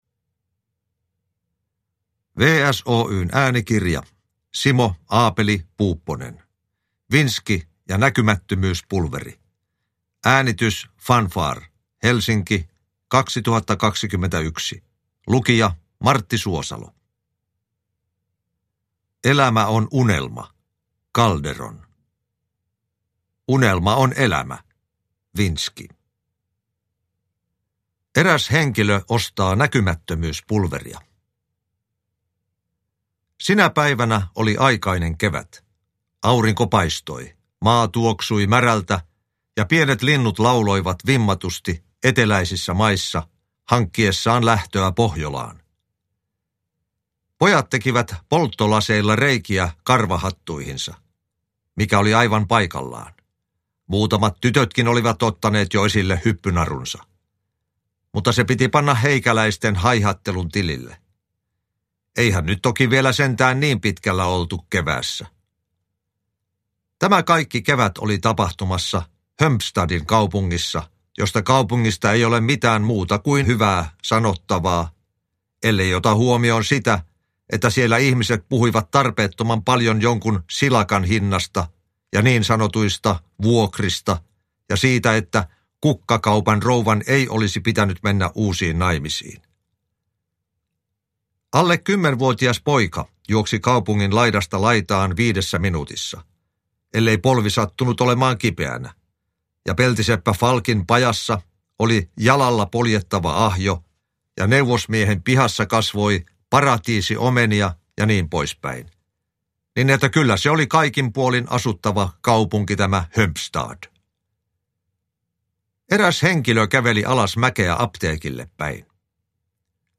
Uppläsare: Martti Suosalo